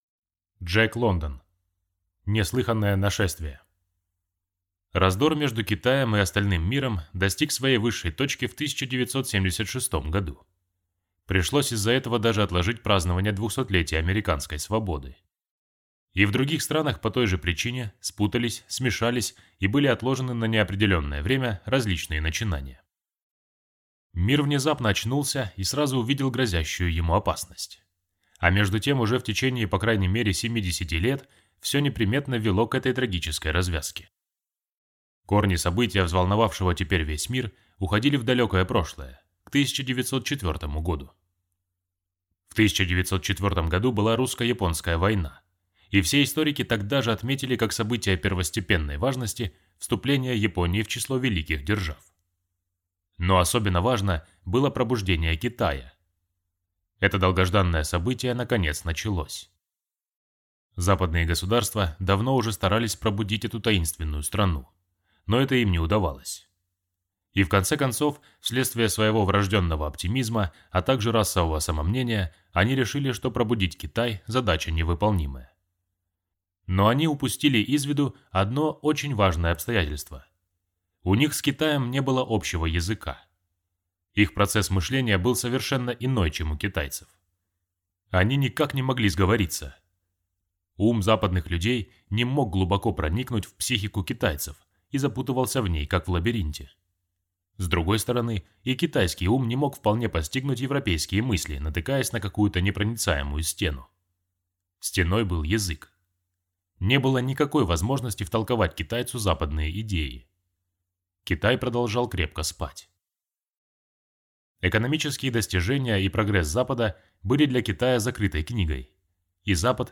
Аудиокнига Неслыханное нашествие | Библиотека аудиокниг